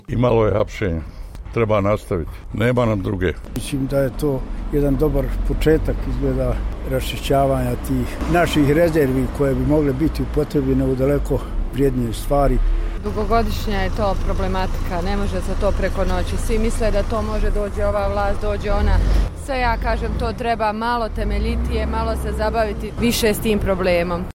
Na pitanje kako komentiraju posljednju pocijsku akciju bivših rukovodioca GRAS-a i sveukupno stanje u komunalnoj privredi, građani Sarajeva su odgovorili:
Građani o komunalnim preduzećima u Kantonu Sarajevo